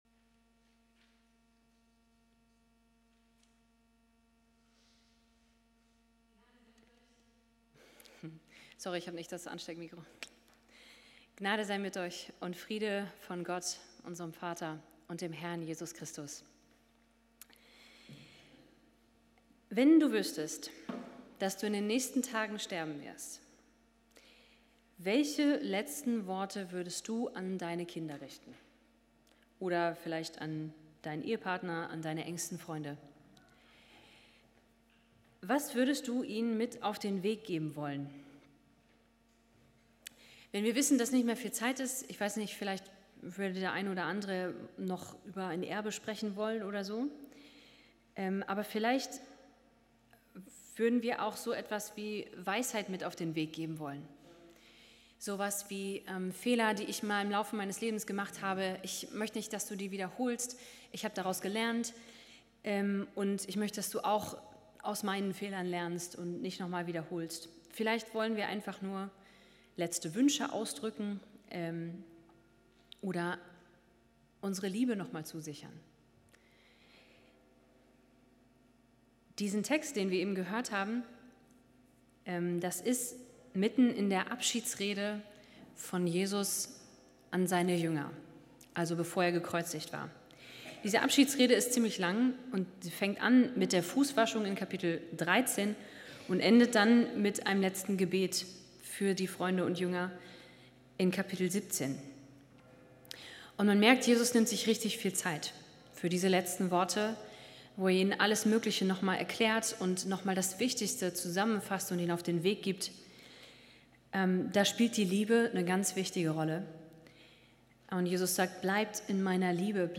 KG Halver Rubrik: Predigt Zentrale Inhalte von